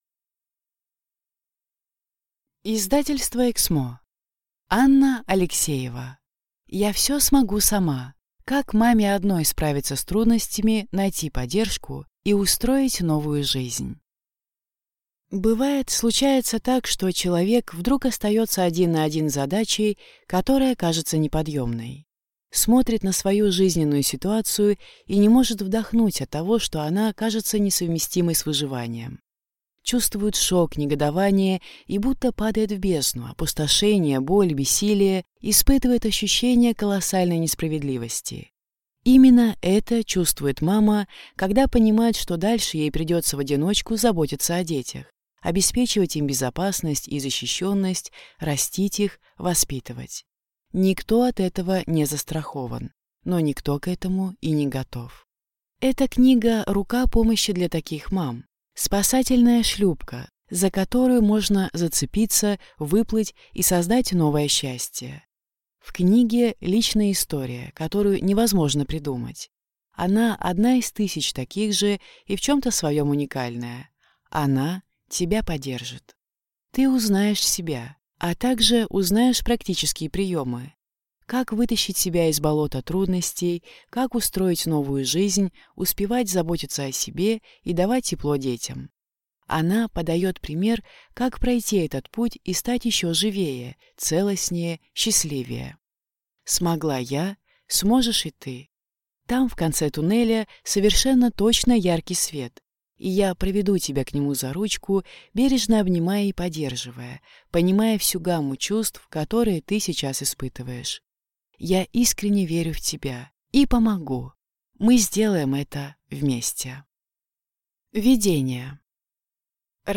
Аудиокнига Я всё смогу сама! Как маме одной справиться с трудностями, найти поддержку и устроить новую жизнь | Библиотека аудиокниг